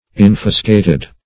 infuscated - definition of infuscated - synonyms, pronunciation, spelling from Free Dictionary Search Result for " infuscated" : The Collaborative International Dictionary of English v.0.48: Infuscated \In*fus"ca*ted\, a. (Zool.)
infuscated.mp3